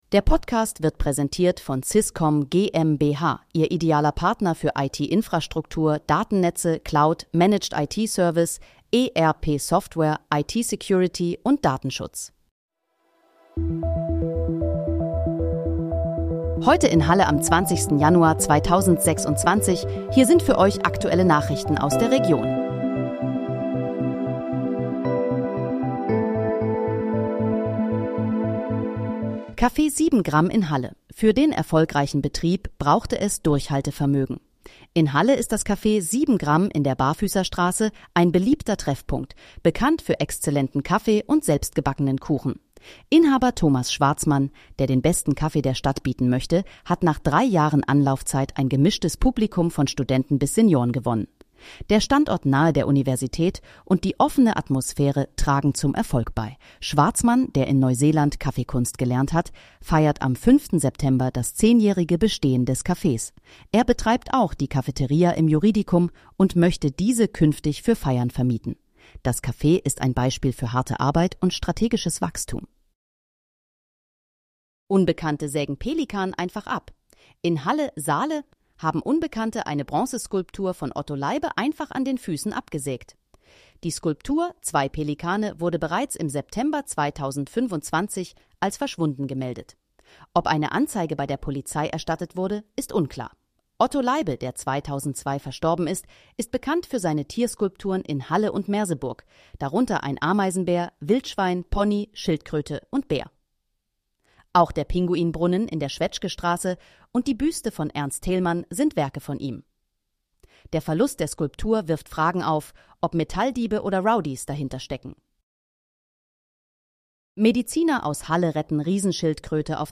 Heute in, Halle: Aktuelle Nachrichten vom 20.01.2026, erstellt mit KI-Unterstützung
Nachrichten